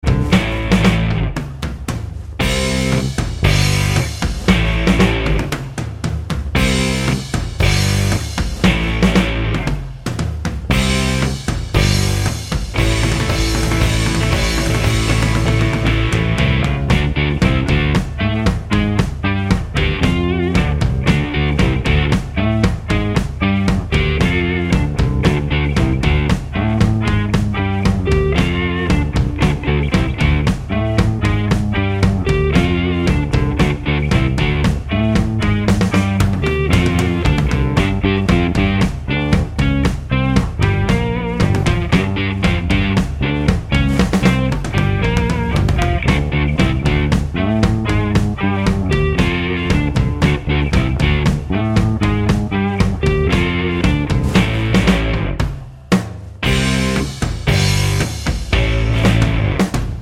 For Solo Male Blues 4:08 Buy £1.50